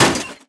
Light metal board collision
Vehicle light impact.